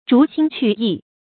逐新趣异 zhú xīn qù yì
逐新趣异发音